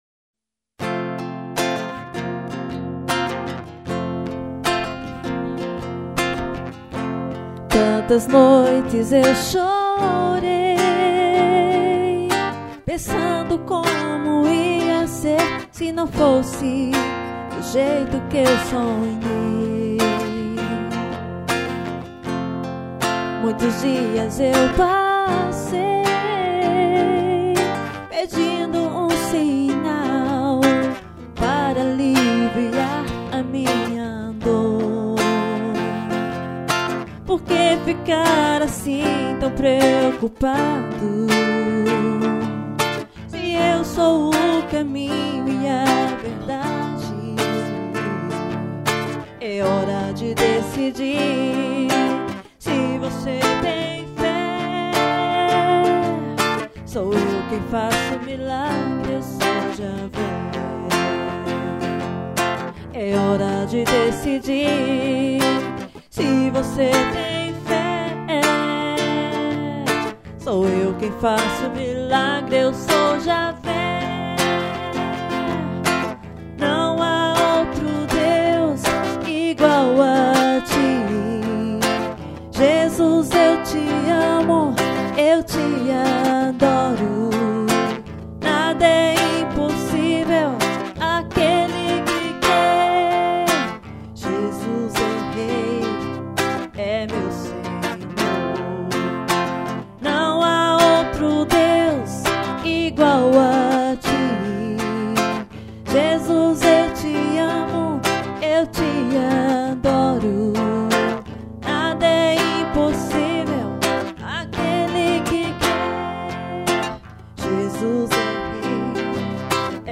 voz
VIOLAÕ